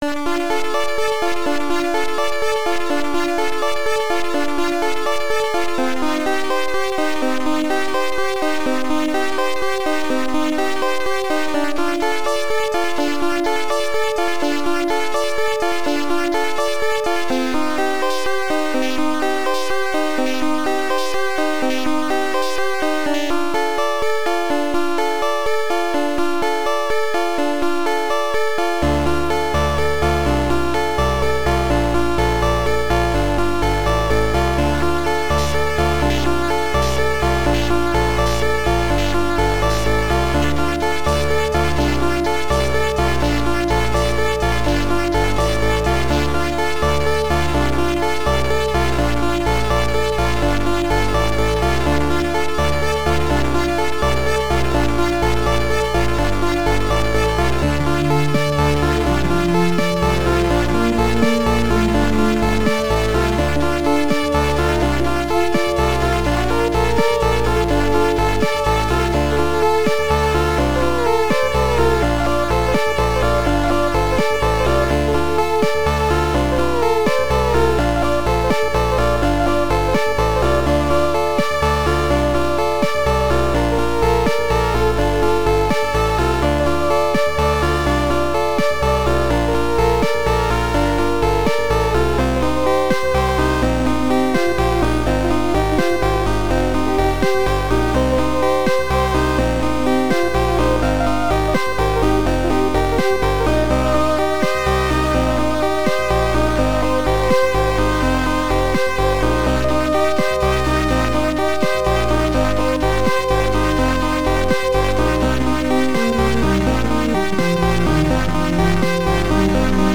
Brian Postma SoundMon module